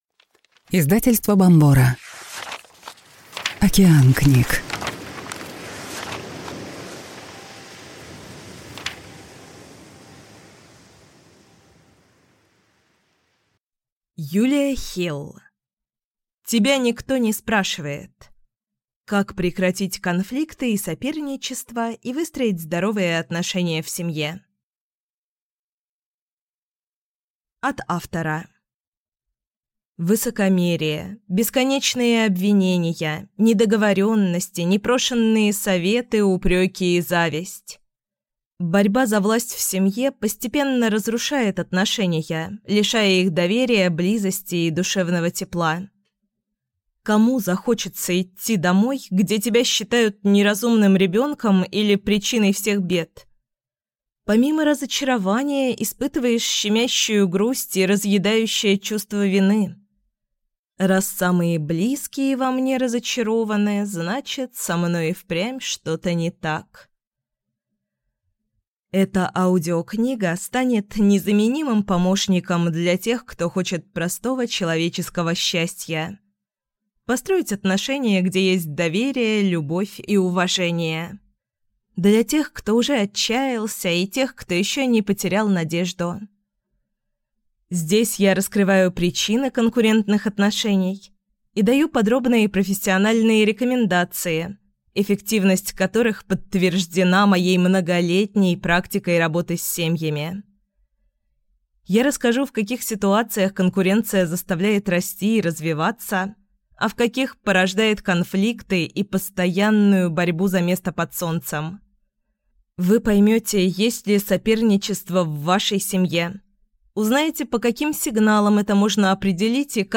Аудиокнига Тебя никто не спрашивает! Как прекратить конфликты и соперничество и выстроить здоровые отношения в семье | Библиотека аудиокниг